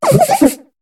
Cri de Couverdure dans Pokémon HOME.